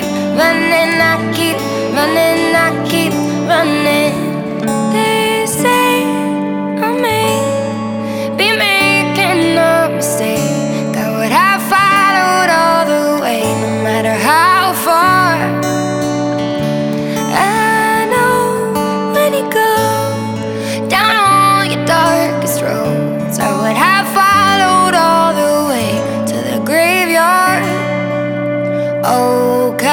• Alternative